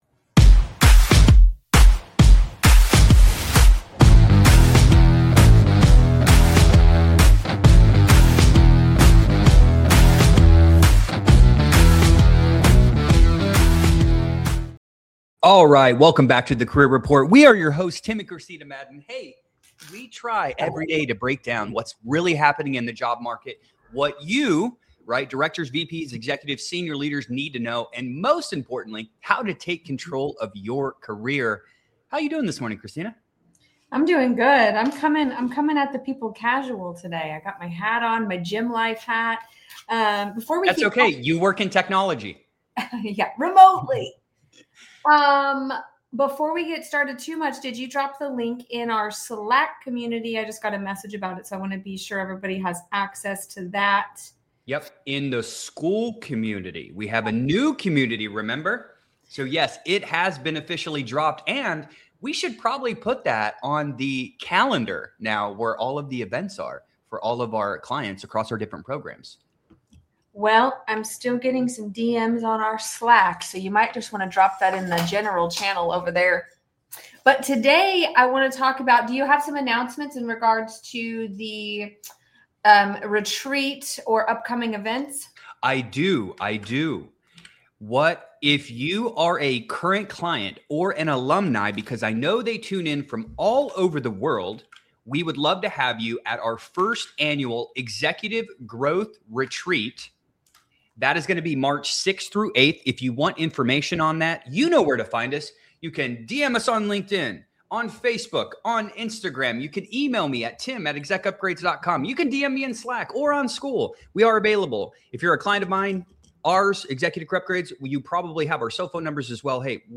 They discuss how strategic volume differs from mass applying, how leaders and recruiters actually respond to outreach, and why fewer, better-targeted actions tend to outperform high-effort scattershot searches. The conversation focuses on clarity, positioning, and knowing where to apply pressure rather than exhausting yourself everywhere at once.